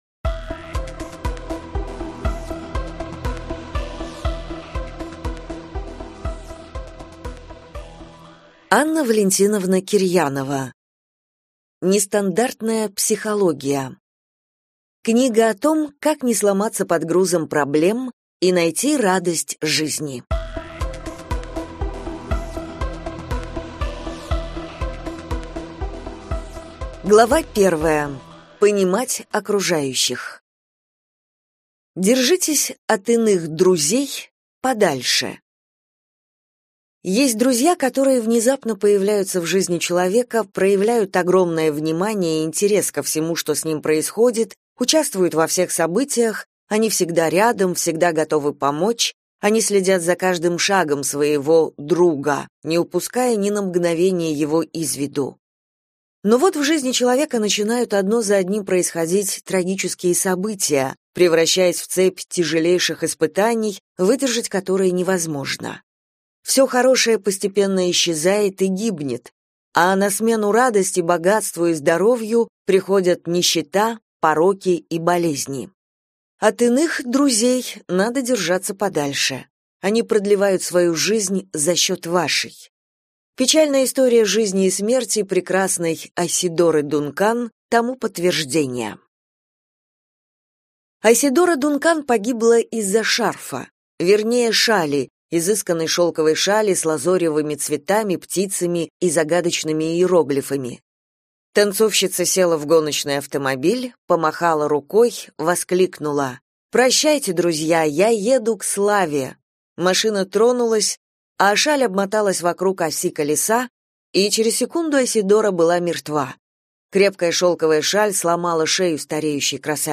Аудиокнига Нестандартная психология. Книга о том, как не сломаться под грузом проблем и найти радость жизни | Библиотека аудиокниг